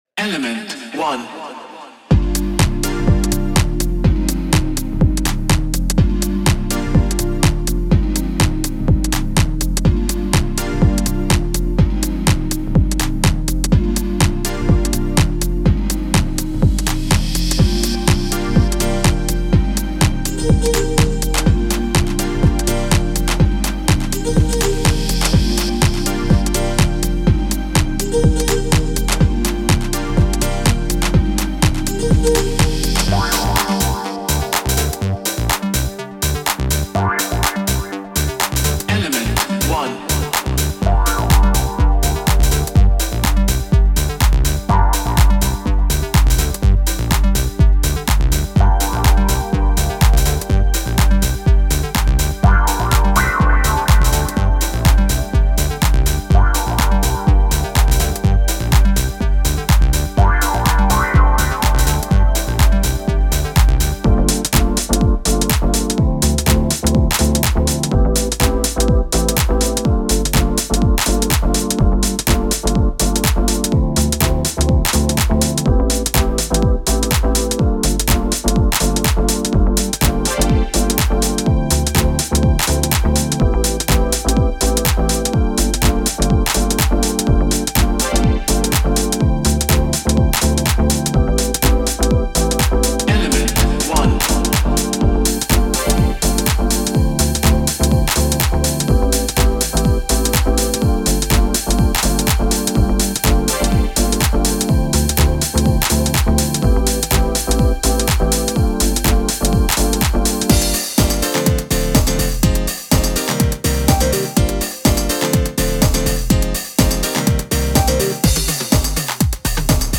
90年代ハウスのノスタルジックな中核を成す、ソウルフルでレイヴ感があり、高揚感のあるコード進行を収録しています。
デモサウンドはコチラ↓
Genre:House